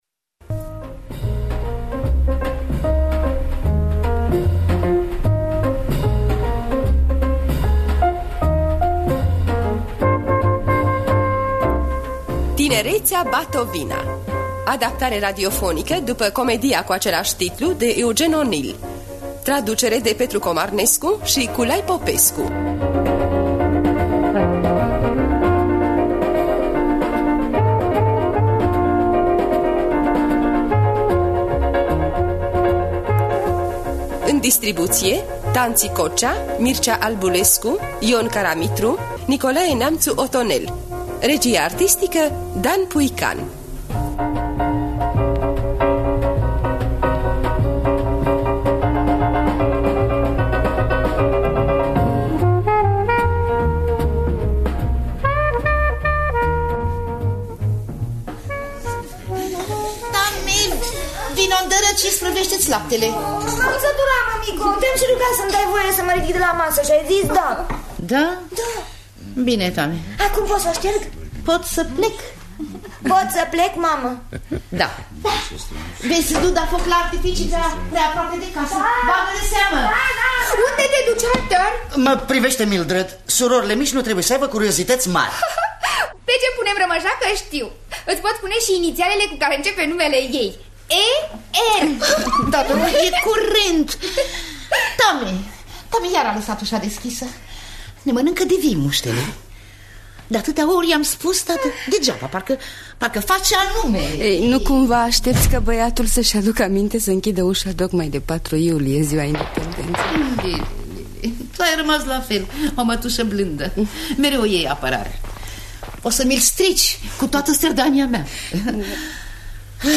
„Tinereţea, bat-o vina!” de Eugene O’Neill – Teatru Radiofonic Online